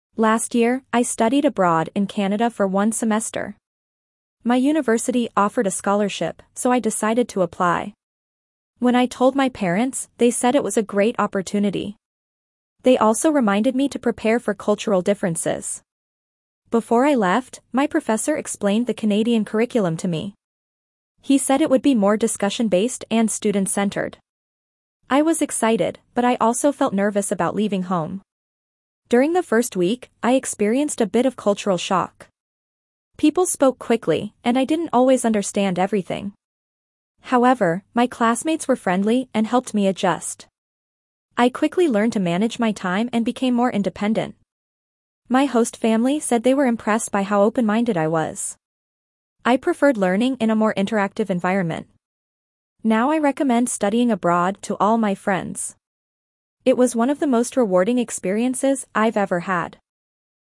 Dictation B2 - Studying Abroad
Your teacher will read the passage aloud.
4.-B2-Dictation-Studying-Abroad.mp3